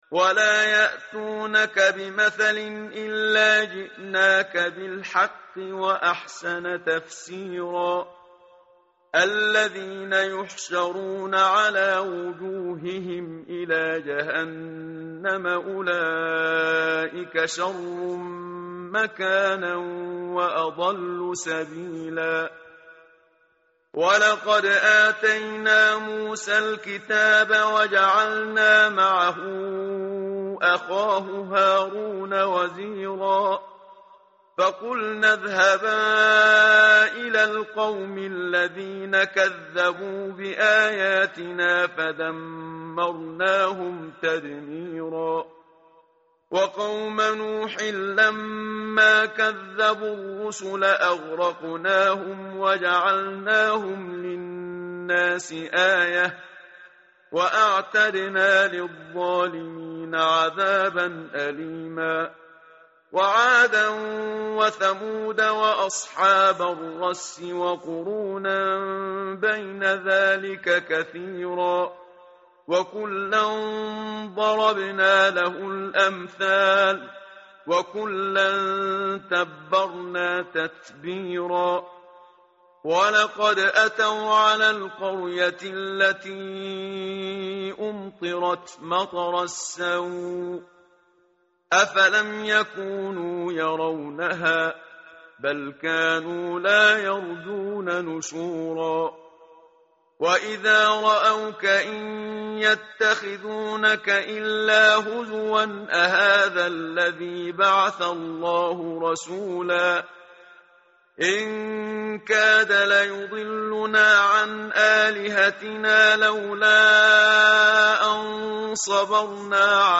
متن قرآن همراه باتلاوت قرآن و ترجمه
tartil_menshavi_page_363.mp3